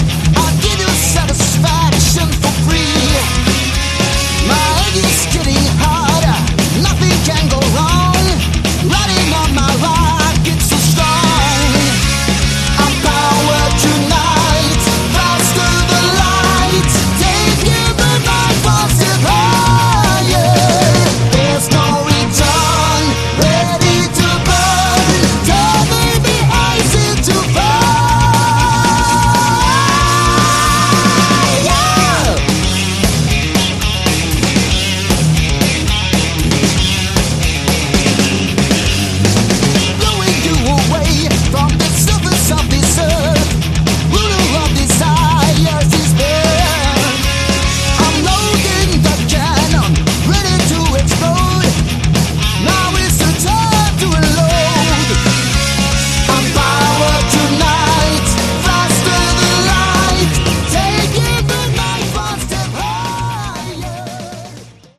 Category: Hard Rock
vocals
guitars
bass
drums
keyboards